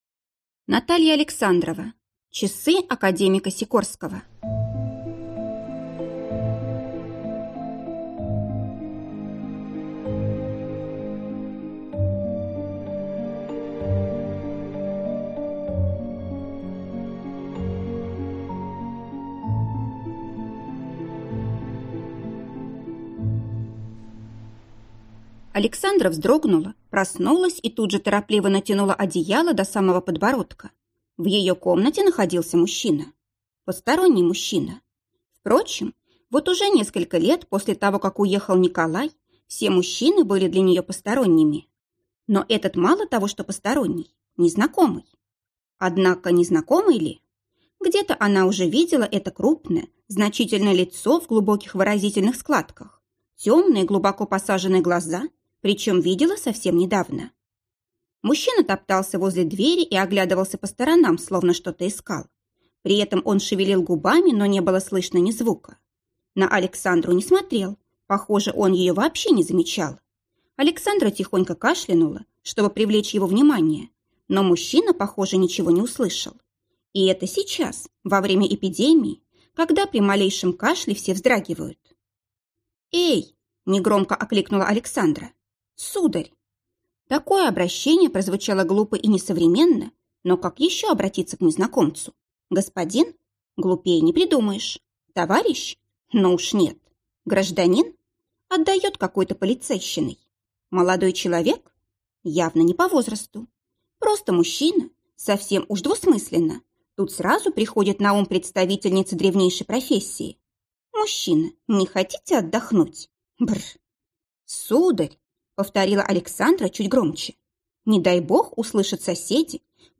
Аудиокнига Часы академика Сикорского | Библиотека аудиокниг